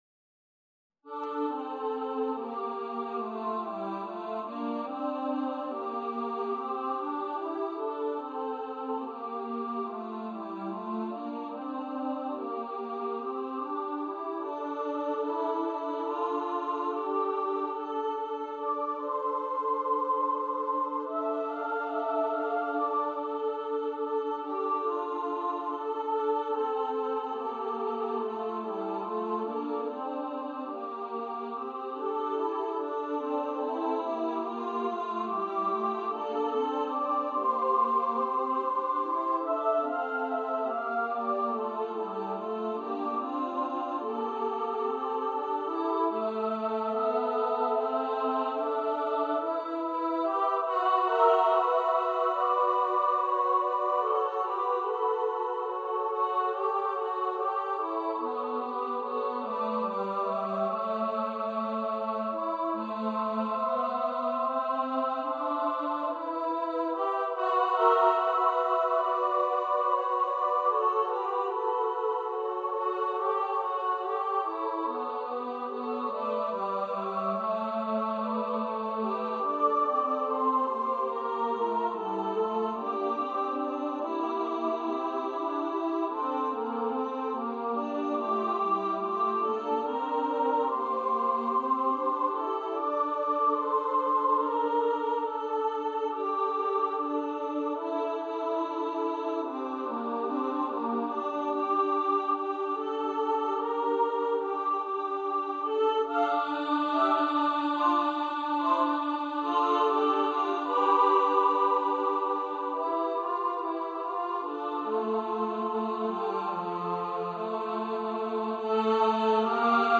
for upper voice choir
for unaccompanied upper voice choir (SSA)
Choir - 3 part upper voices